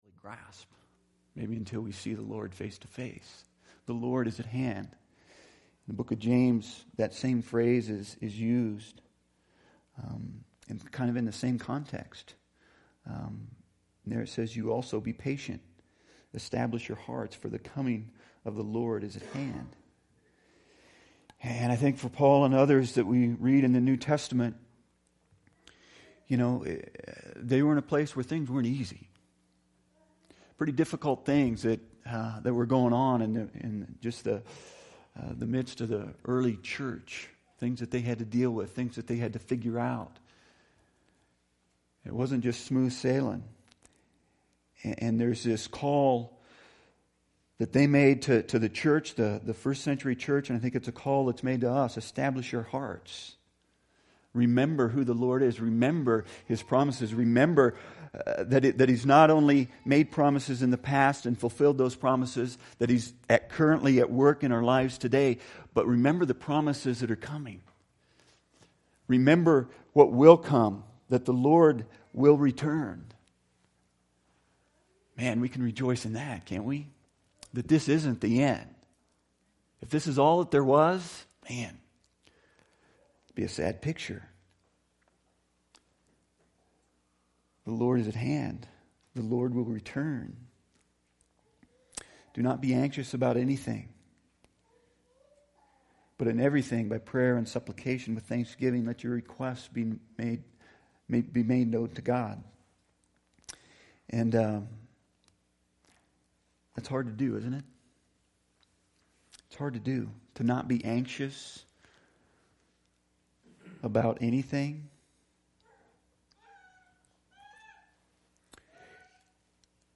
Reflection on the Year (The First 14 Minutes of the Message Were Lost)
BG Archives Service Type: Sunday Speaker